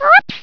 Whoops.wav